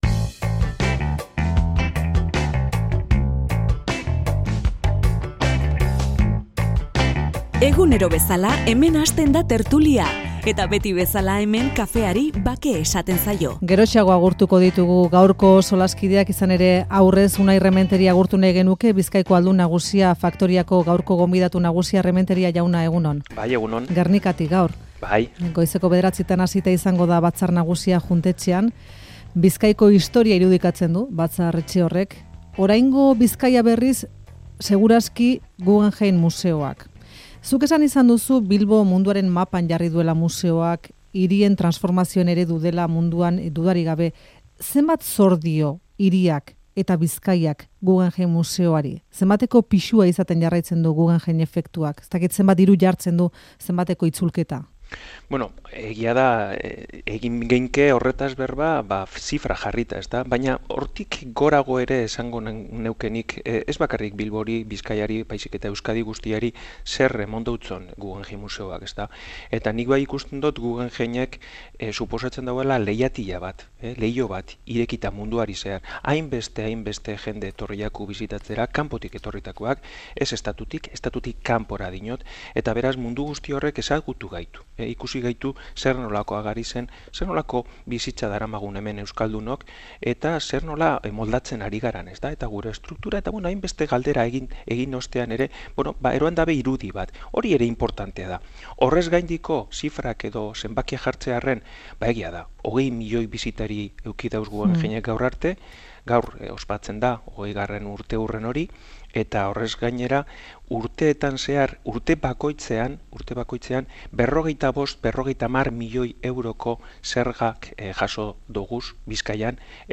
Unai Rementeria, Bizkaiko Ahaldun Nagusia, Euskadi Irratiko Faktorian.